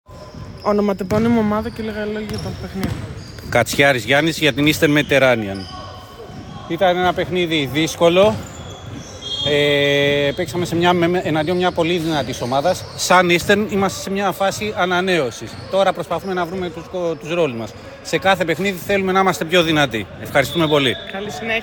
GAMES INTERVIEWS